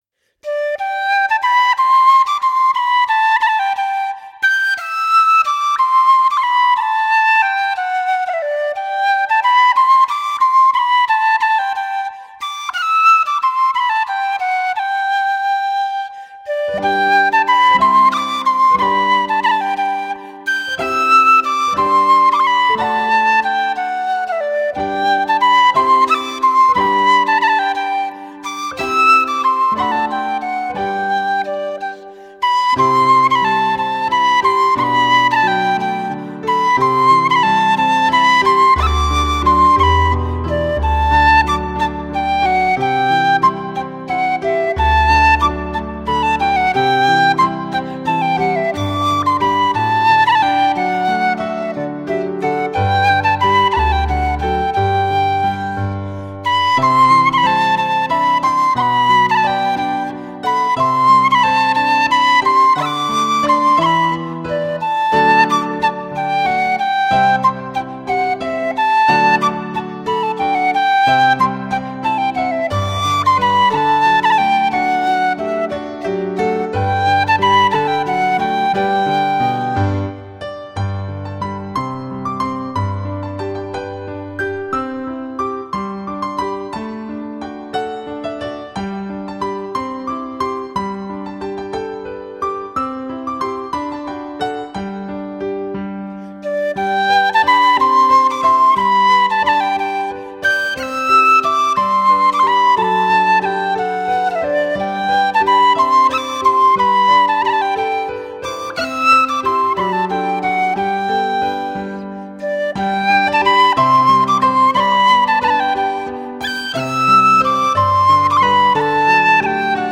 笛